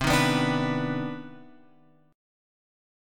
CmM9 chord {x 3 1 4 3 x} chord